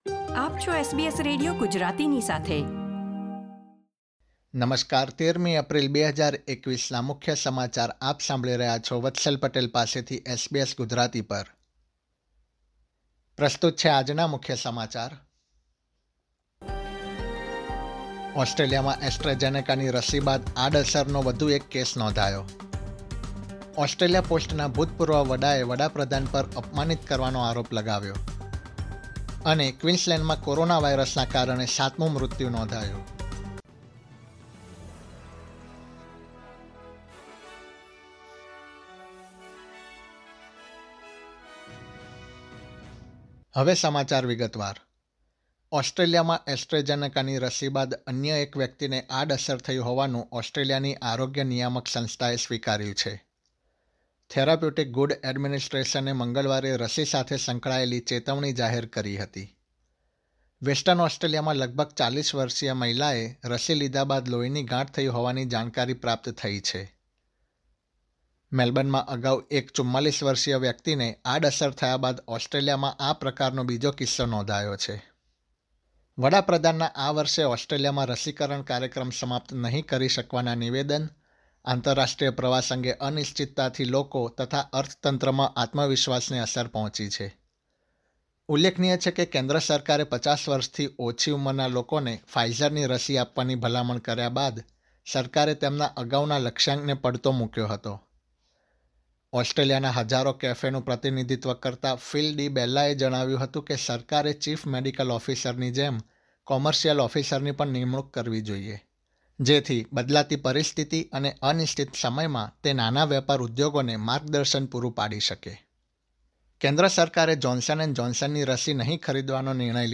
gujarati_1304_newsbulletin.mp3